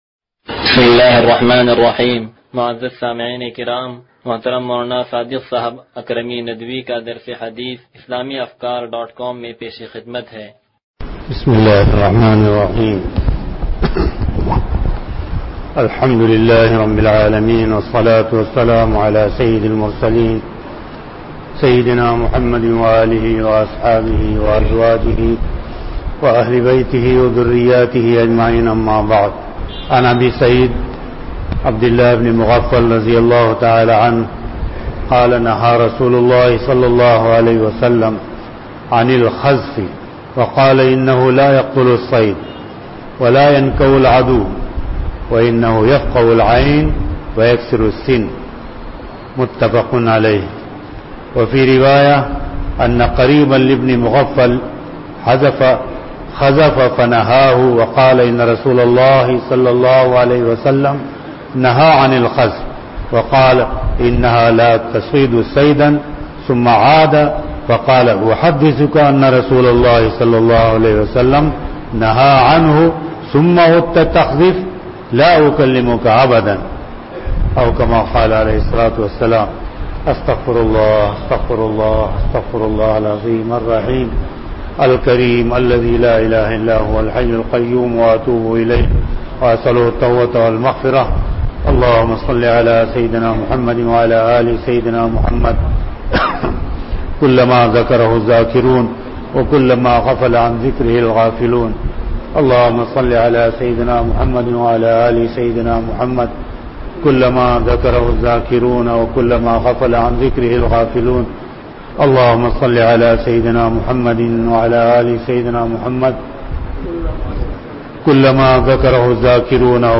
درس حدیث نمبر 0176